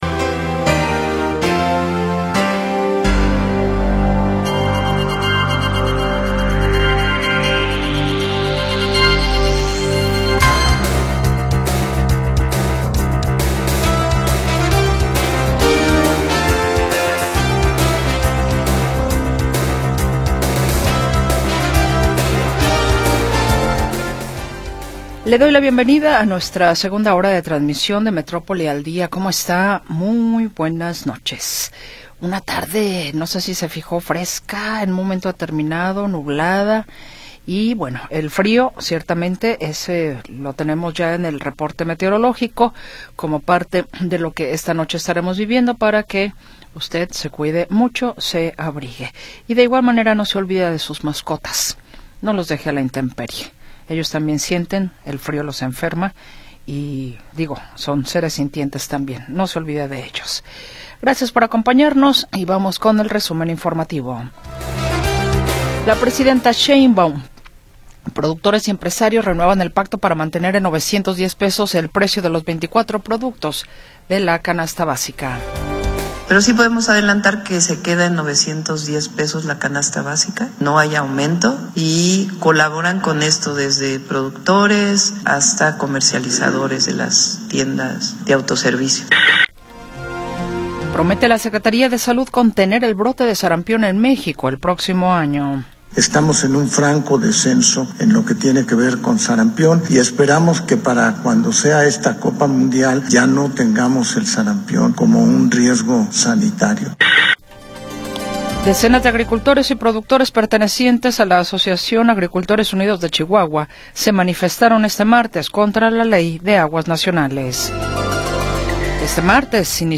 Segunda hora del programa transmitido el 18 de Noviembre de 2025.